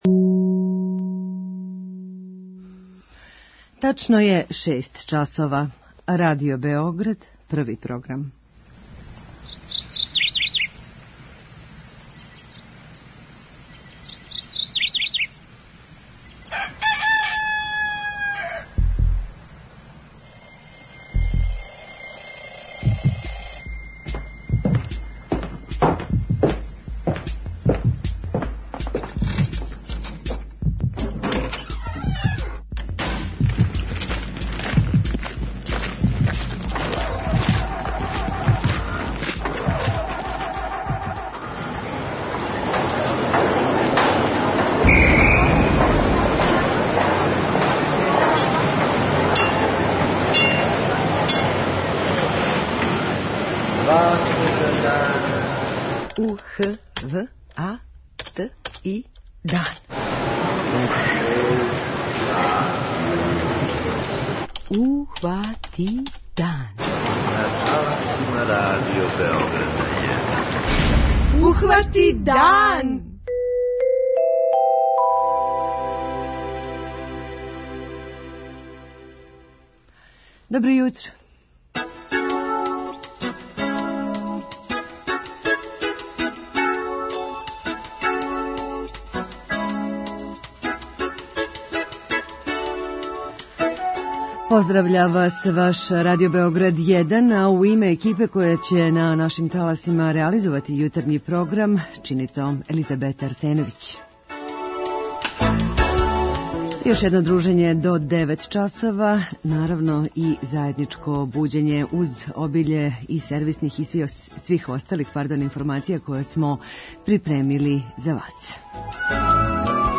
преузми : 32.38 MB Ухвати дан Autor: Група аутора Јутарњи програм Радио Београда 1!